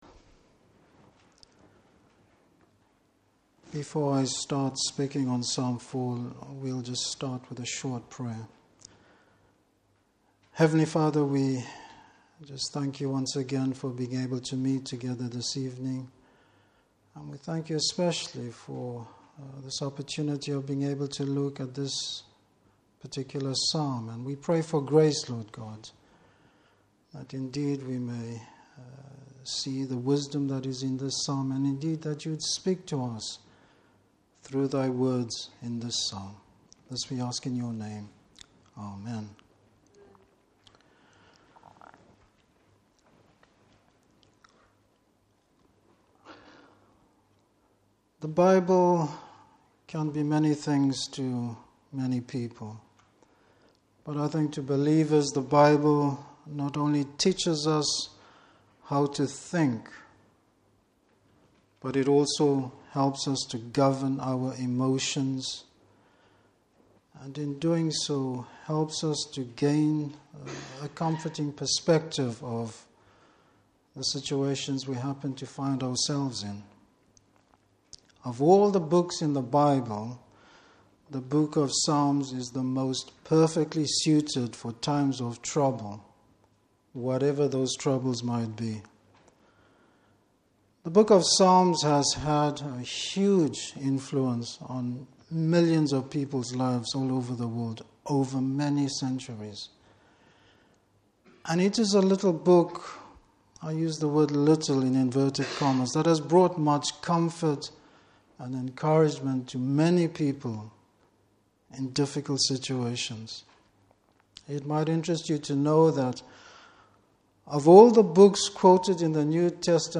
Service Type: Evening Service Bible Text: Psalm 4.